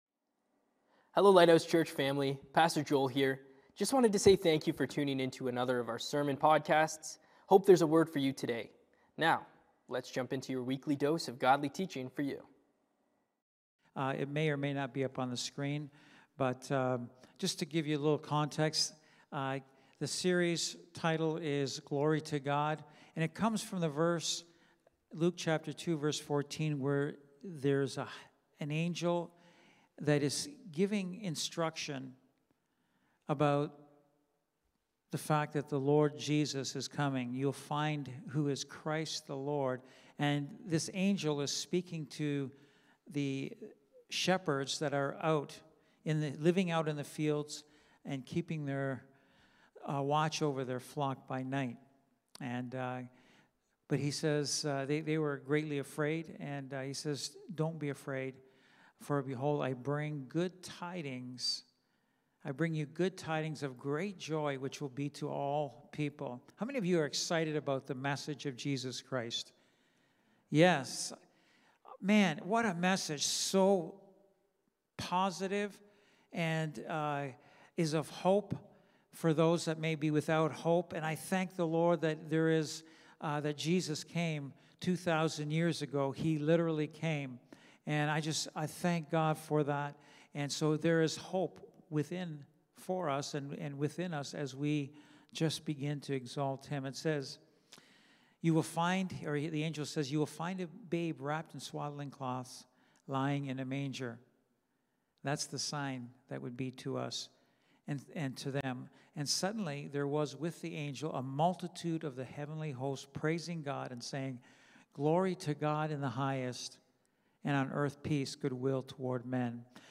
Wednesday Night Bible Study
Lighthouse Niagara Sermons